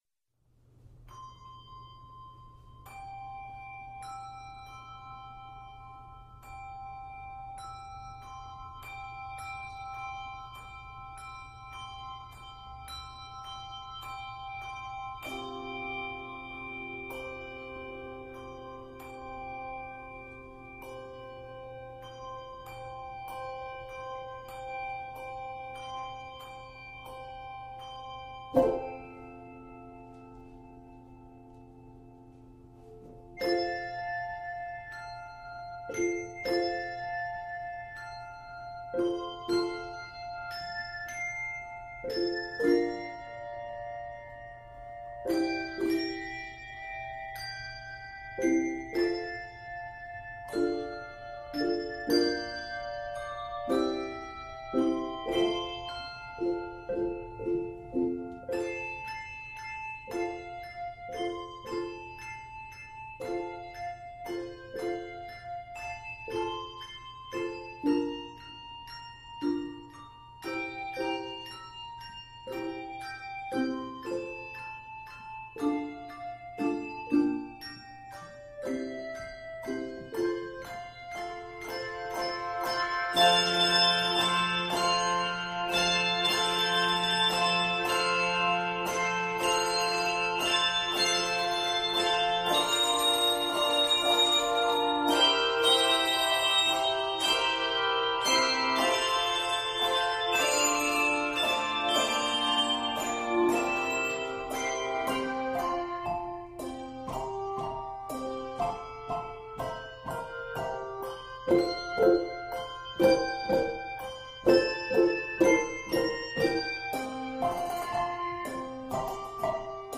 It is 137 measures and is arranged in F Major.